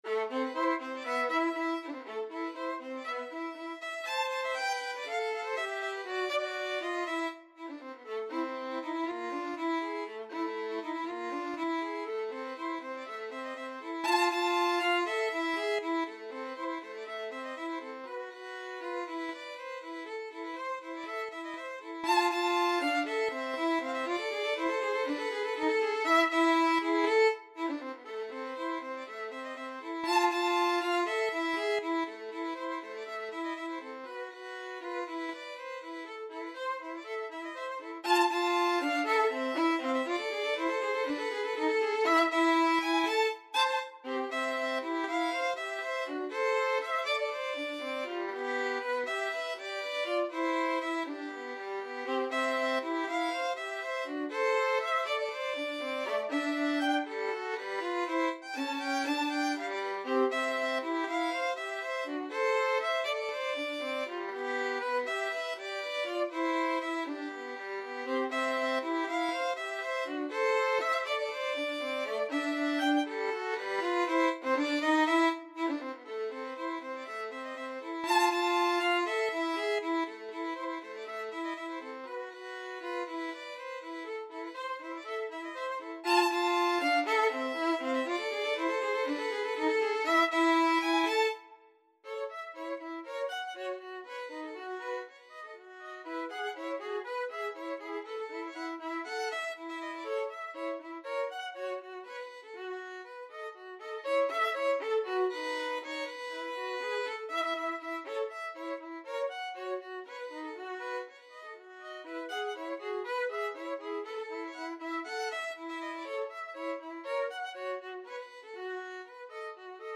2/4 (View more 2/4 Music)
Allegretto Misterioso = 120
Classical (View more Classical Violin Duet Music)